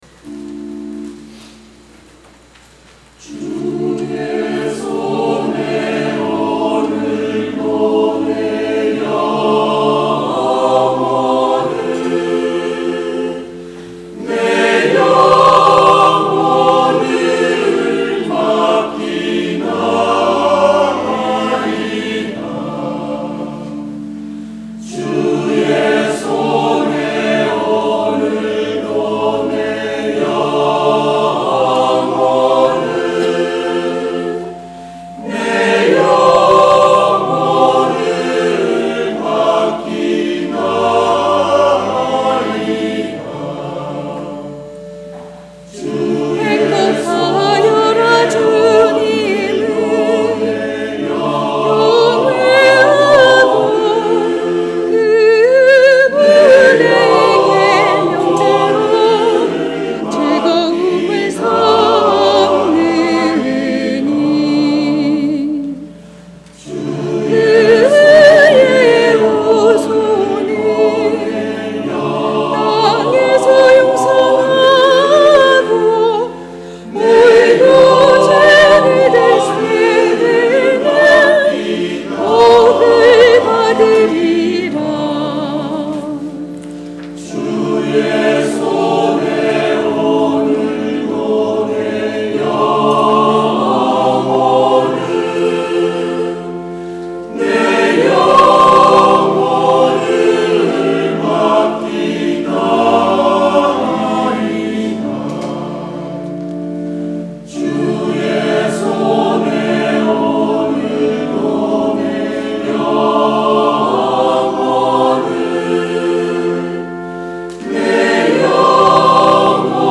성가대 - 109.